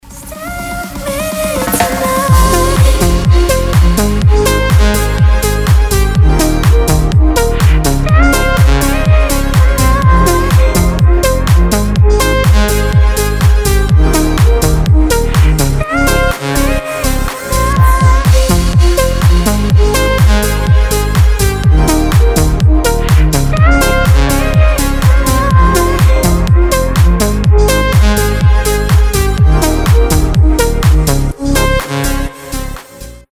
красивый женский голос
house